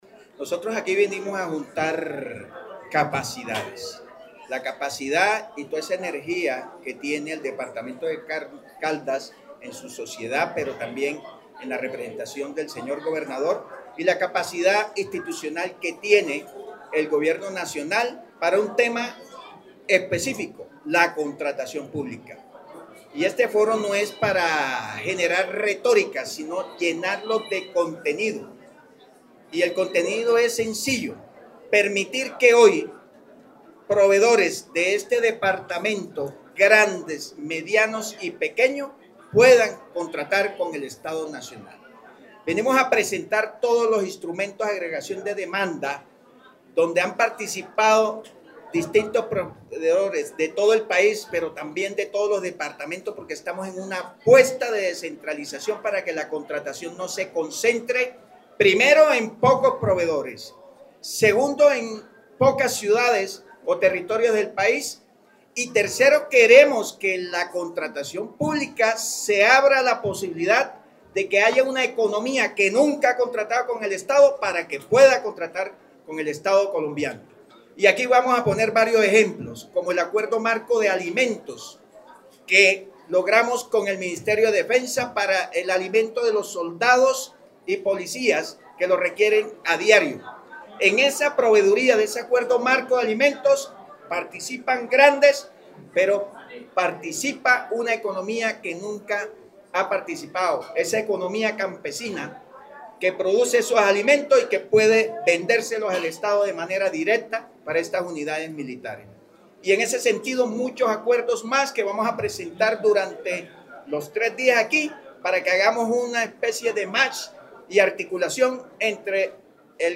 Con la presencia del director general de la Agencia Nacional de Contratación Pública-Colombia Compra Eficiente, Cristóbal Padilla Tejeda, y el gobernador de Caldas, Henry Gutiérrez Ángel, se instaló en Manizales el Foro de Contratación Estatal y Compras Públicas.
Director general de la Agencia Nacional de Contratación Pública Colombia Compra Eficiente, Cristóbal Padilla Tejeda.